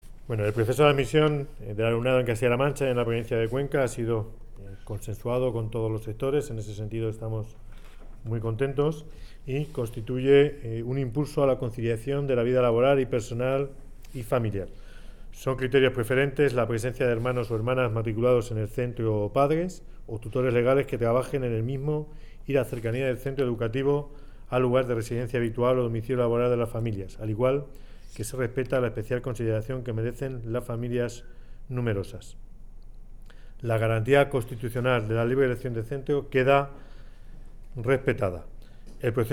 Corte Godoy proceso de admisión
Consejería de Educación, Cultura y Deportes Martes, 14 Febrero 2017 - 3:15pm El delegado de la Junta en Cuenca, Ángel Tomás Godoy, afirma que el nuevo proceso de admisión es un paso adelante en la conciliación de las familias y garantiza la libre elección de centro. corte_godoy_proceso_admision.mp3 Descargar: Descargar Provincia: Cuenca